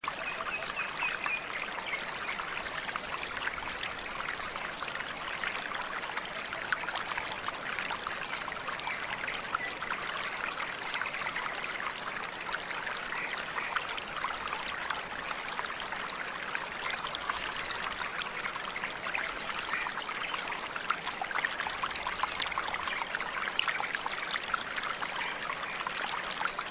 Nature Sounds
Rivière Paisible en Forêt